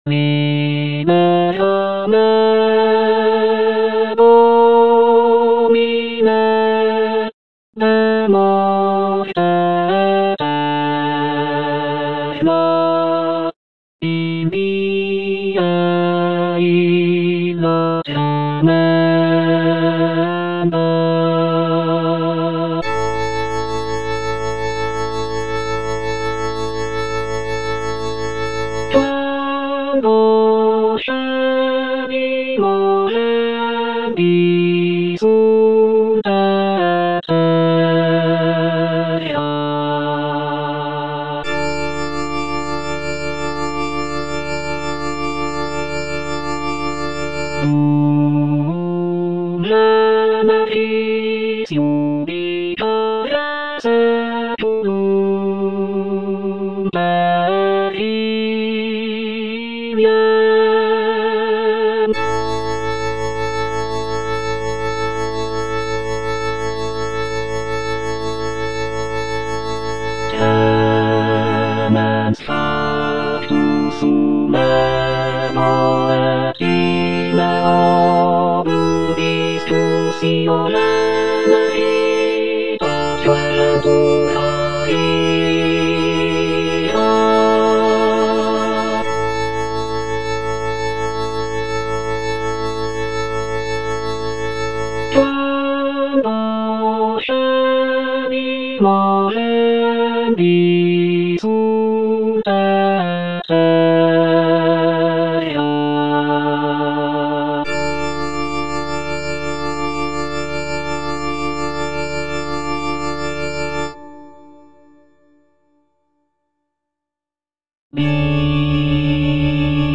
F. VON SUPPÈ - MISSA PRO DEFUNCTIS/REQUIEM Libera me (All voices) Ads stop: auto-stop